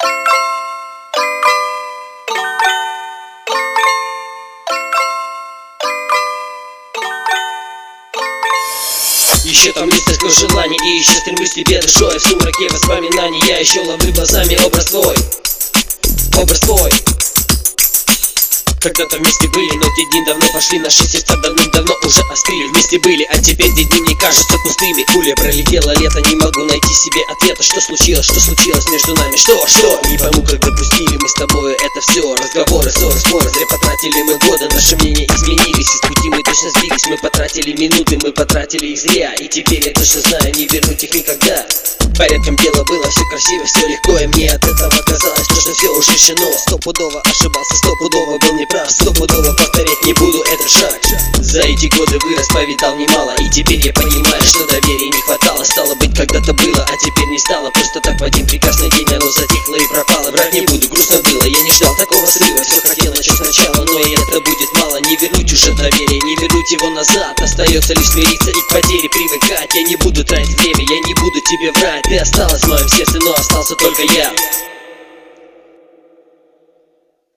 • Жанр: Рэп
Track ne svedion..ka4estvo i zapisi o4eni golimoe...nikakih Pritenziiiiiii......